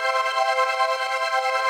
SaS_MovingPad05_140-C.wav